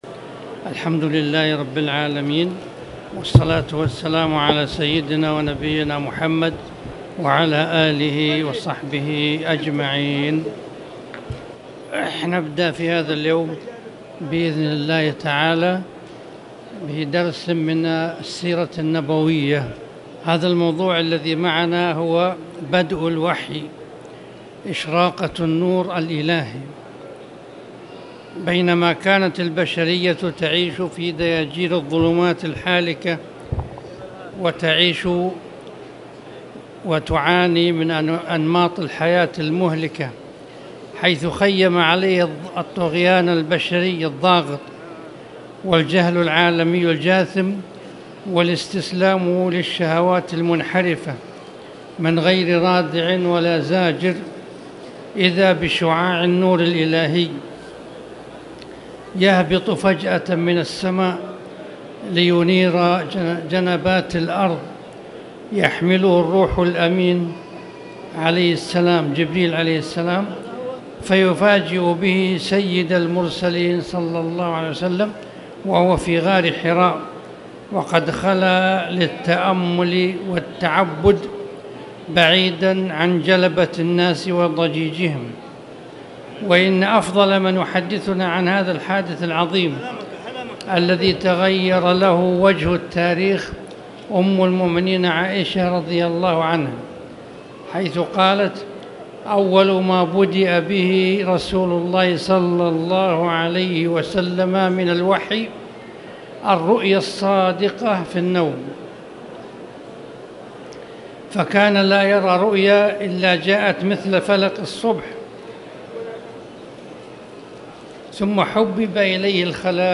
تاريخ النشر ٣ جمادى الآخرة ١٤٣٨ هـ المكان: المسجد الحرام الشيخ